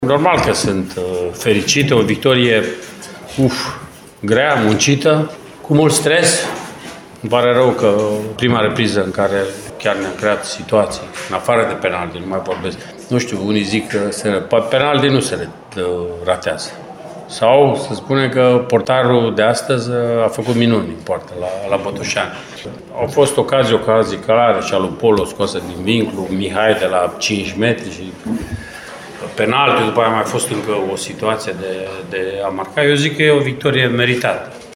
”Uff, ce victorie și ce stress!” a exclamat la conferința de presă, de după meci, antrenorul principal al arădenilor, Mircea Rednic: